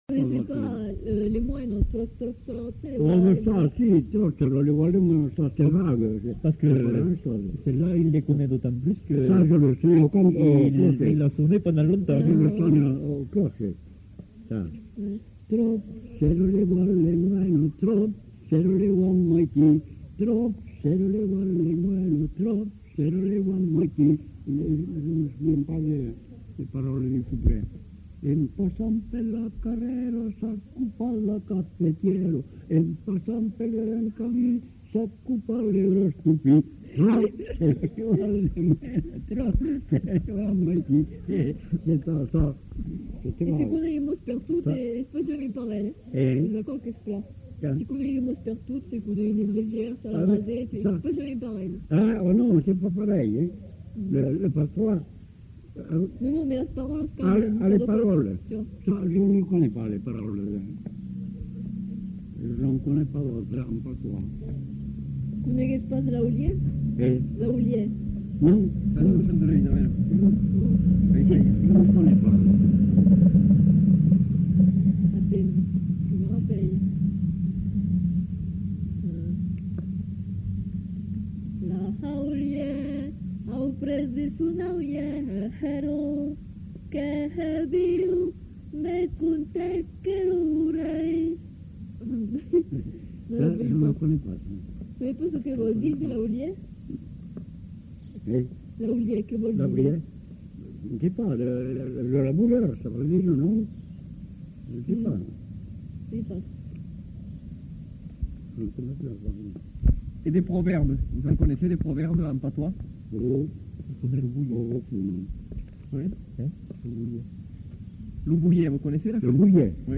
Pastorale languedocienne